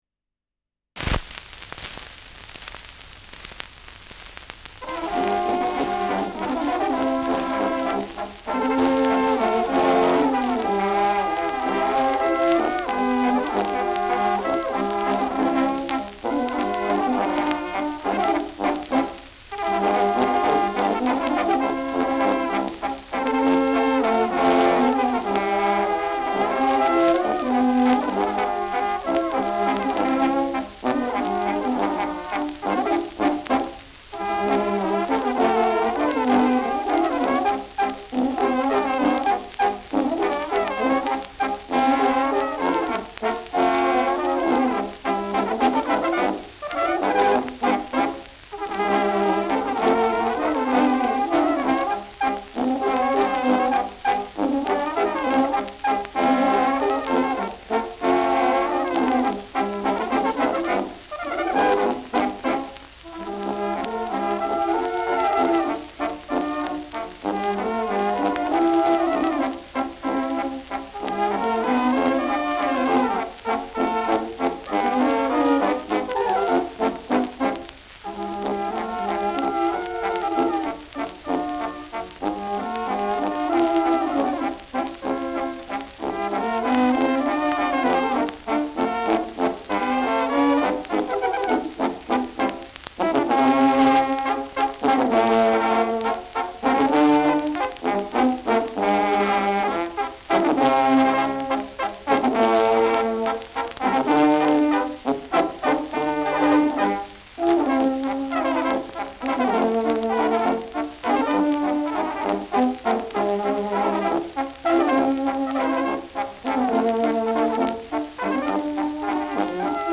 Schellackplatte
Die Bläser spielen auffällig unsauber.
[Salzburg?] (Aufnahmeort)